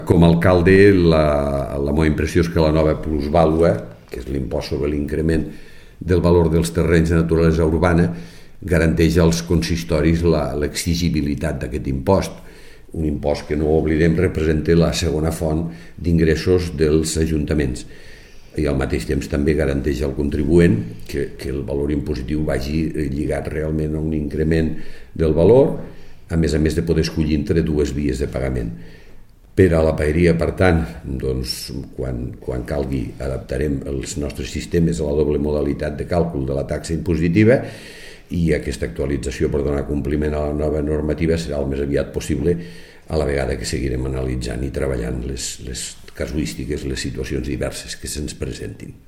La Paeria està analitzant, també, la sentència del TC Compartir Facebook Twitter Whatsapp Descarregar ODT Imprimir Tornar a notícies Fitxers relacionats Tall de veu de l'alcalde Miquel Pueyo sobre la nova plusvàlua (1.2 MB) T'ha estat útil aquesta pàgina?